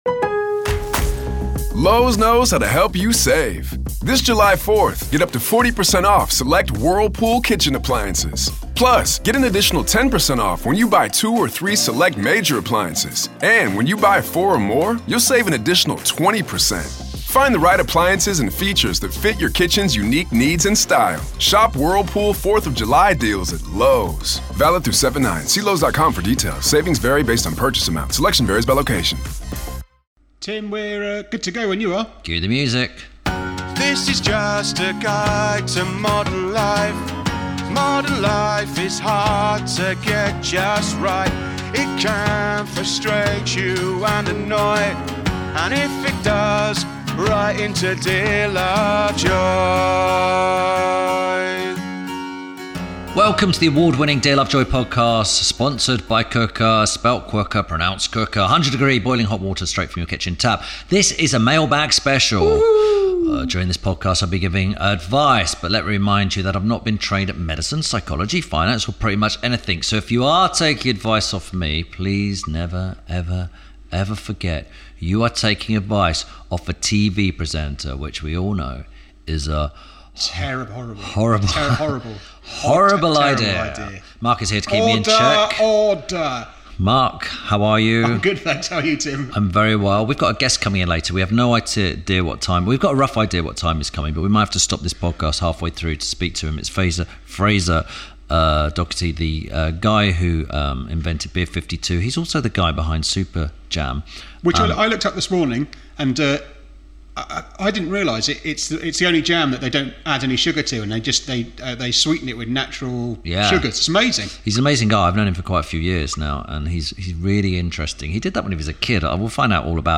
Tim Lovejoy and his producer began a mailbag episode